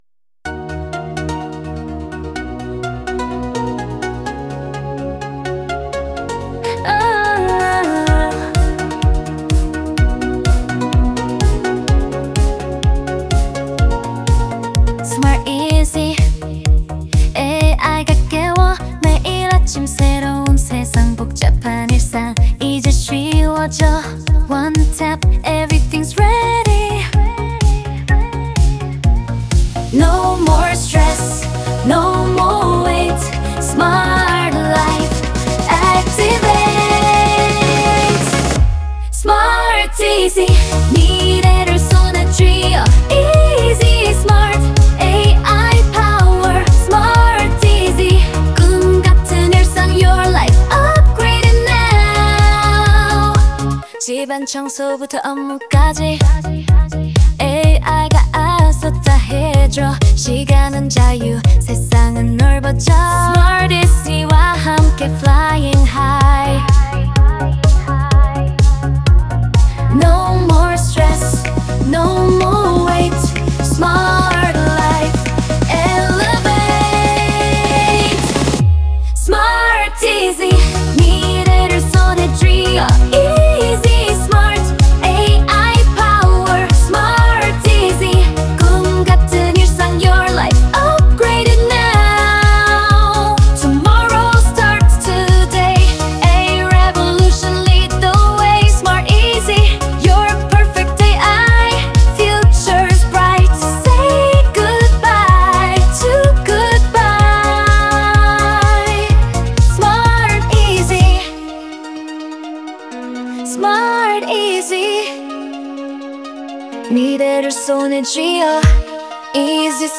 corporate pop, synthwave fusion, futuristic electronic, 128 BPM, energetic major key, clean modern production, driving synth bass, arpeggiated leads, crisp hi-hats, uplifting pads, subtle vocal chops, rising synths intro, synth glow fade outro, logo sound sting end, professional commercial mix, tech